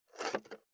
491包丁,厨房,台所,野菜切る,
効果音